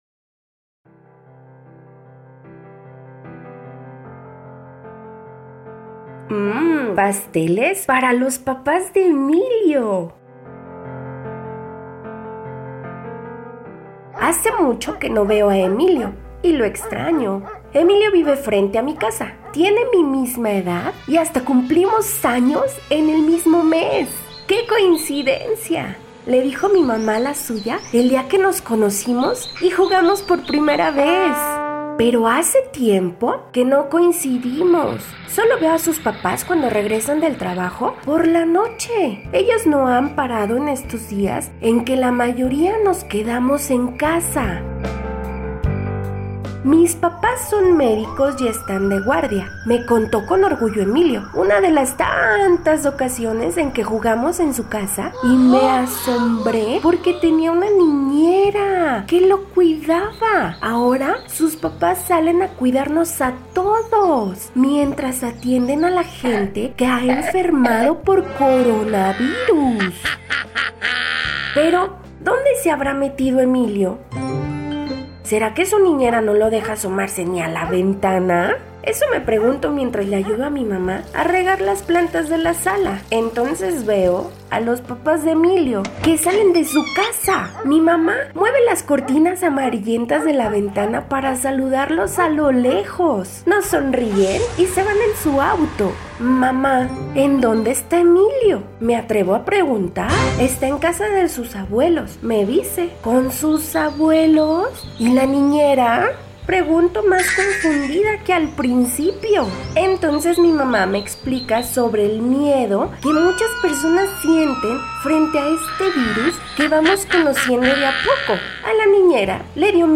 Un audiolibro de AMONITE